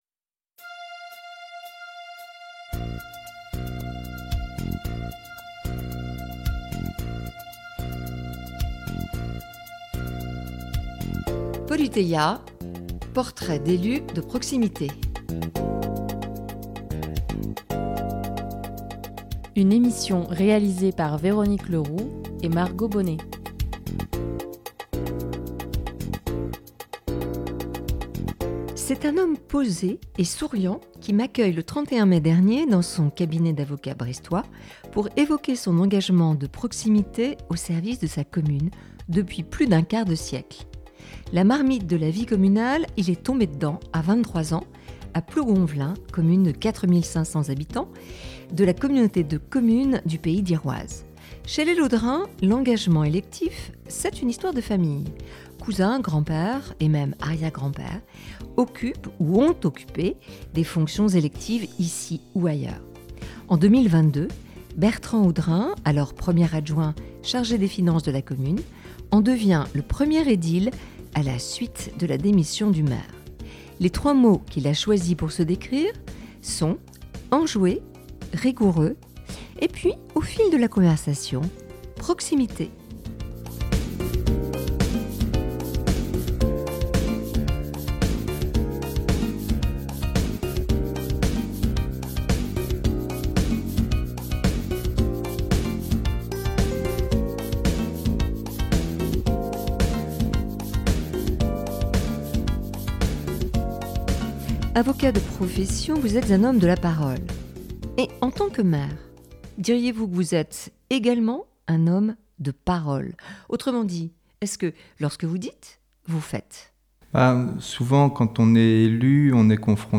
C’est un homme posé et souriant qui m’accueille ce 30 mai 2024 dans son cabinet d’avocat brestois pour évoquer son engagement de proximité au service de sa commune… depuis plus d’un quart de siècle !